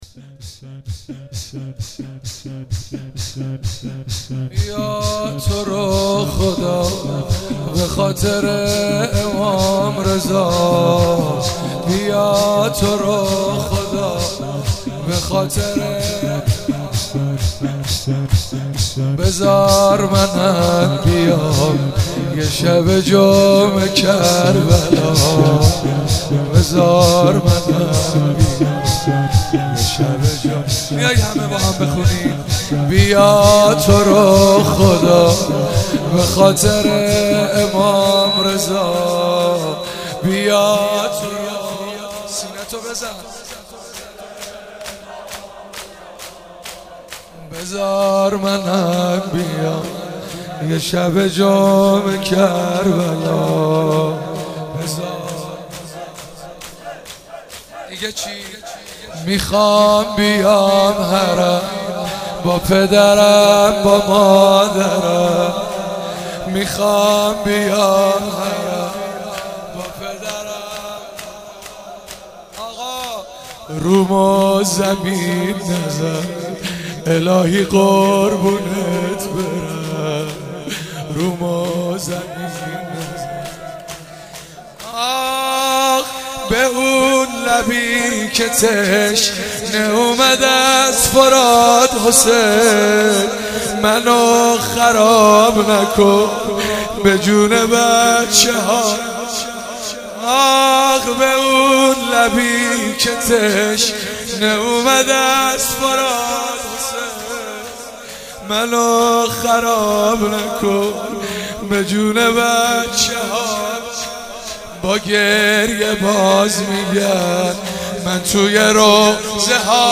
07 heiate alamdar mashhad.mp3